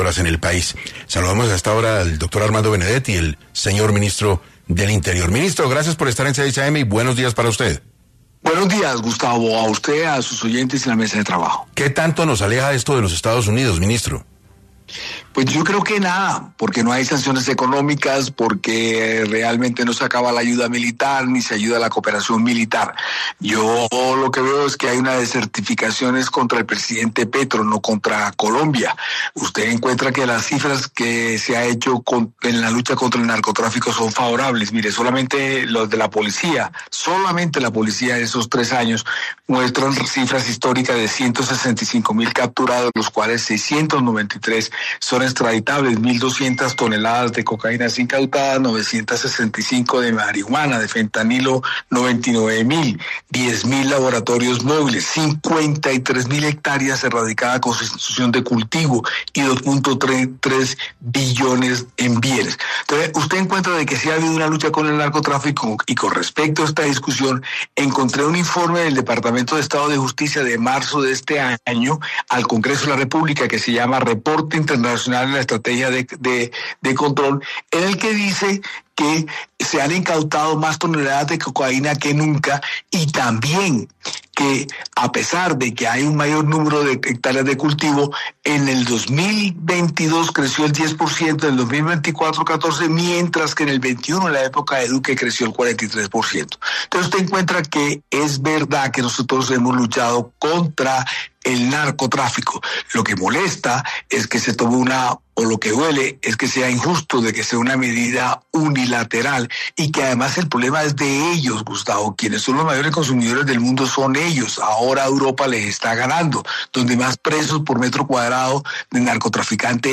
El ministro de Interior habló en 6AM de Caracol Radio sobre la descertificación de Colombia por parte de Estados Unidos.